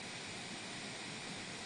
氛围 " 氛围，白噪声
描述：白噪音的氛围。
Tag: 气氛 白色-noise